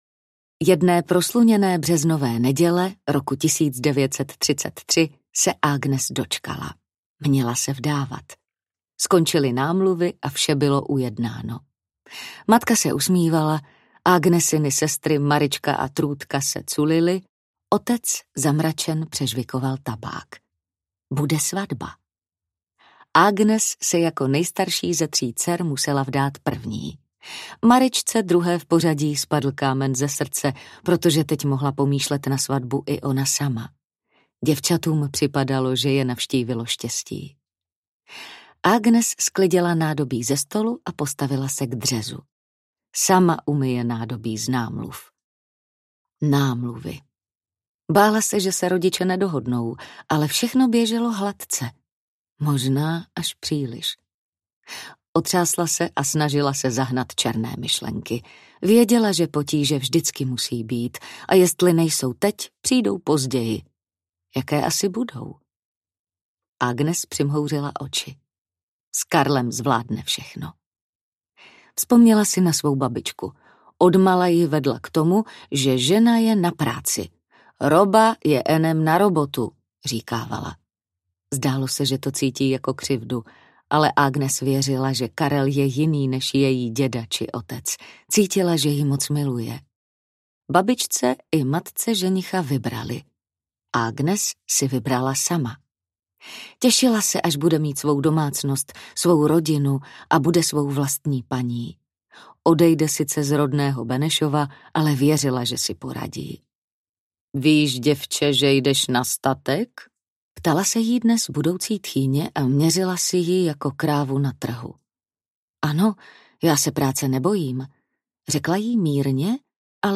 Dědictví audiokniha
Ukázka z knihy
Vyrobilo studio Soundguru.